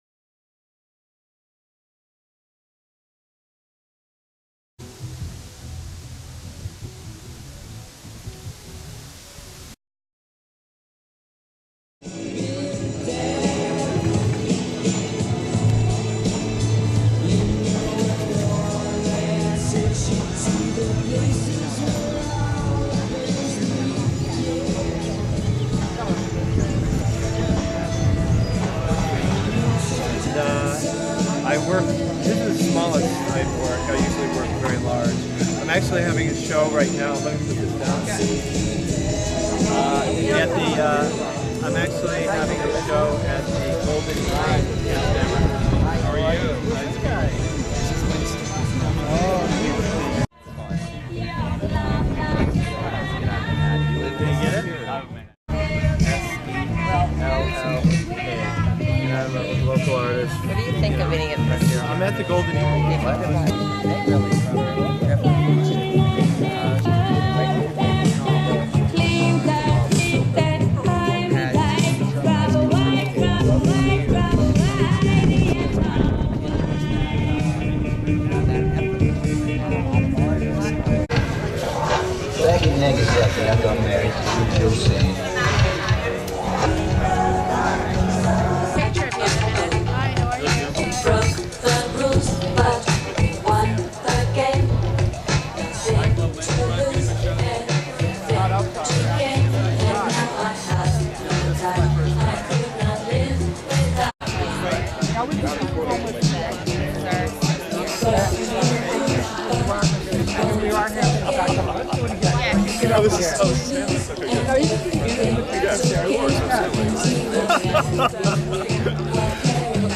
Ross Bleckner hosted a benefit for AIDS under a tent at his legendary house which belonged to Truman Capote.